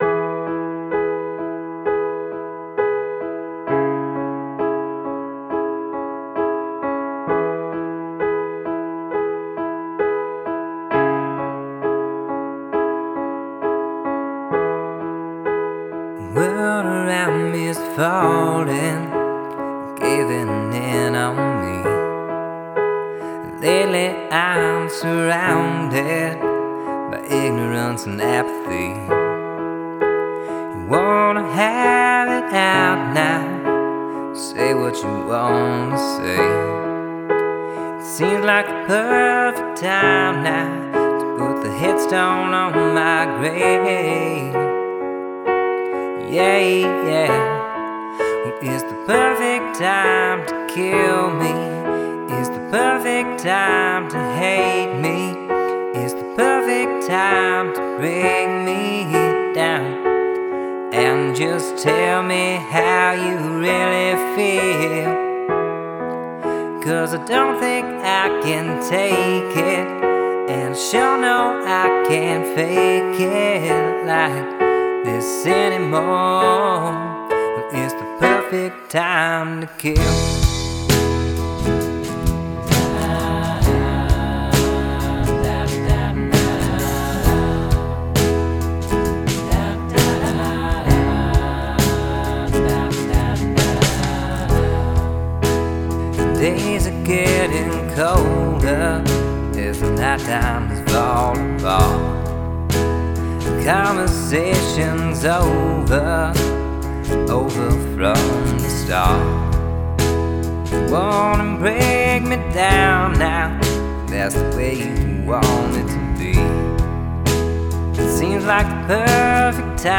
Genre: singersongwriter.